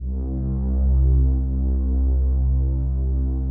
PAD 47-2.wav